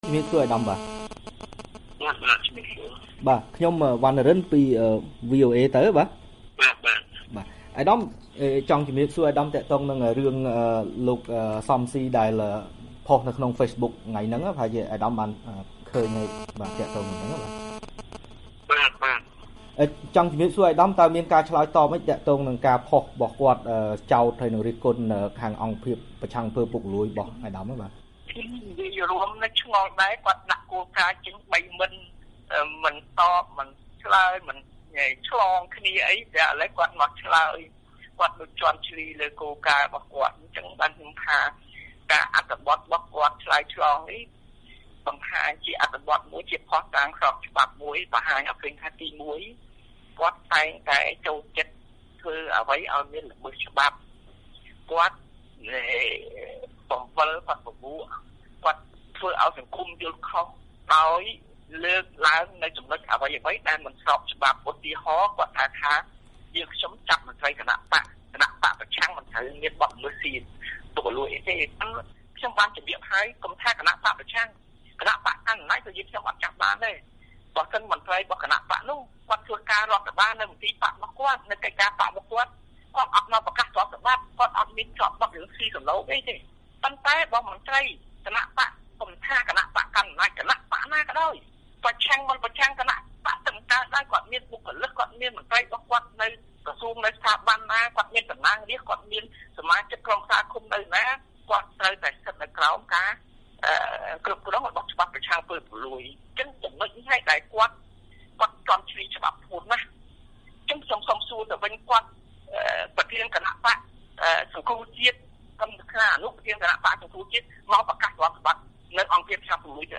បទសម្ភាសន៍ VOAផ្តាច់មុខ៖ ប្រធានអង្គភាពប្រឆាំងអំពើពុករលួយ បដិសេធការរិះគន់ជុំវិញករណីស៊ើបអង្កេត លោក កឹម សុខា